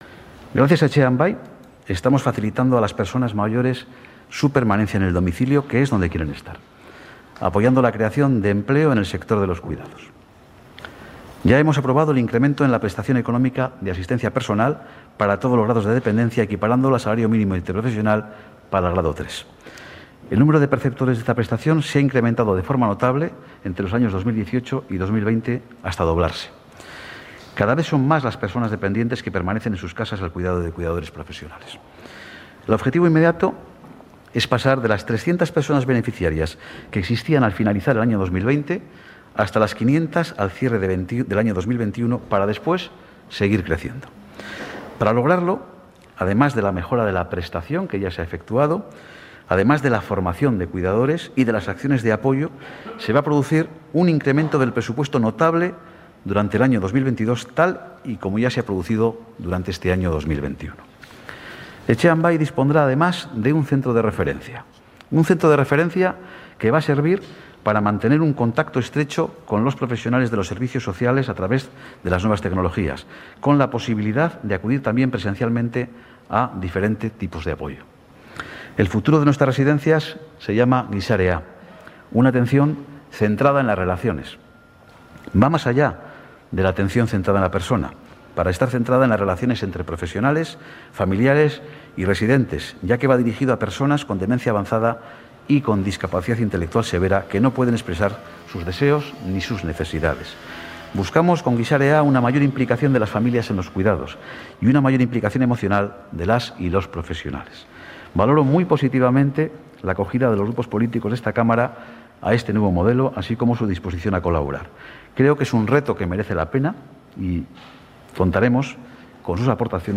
El diputado general de Araba, Ramiro González, ha anunciado en el debate de política general, un plan de inversiones 2022-2025 dotado con 500 millones de euros para proyectos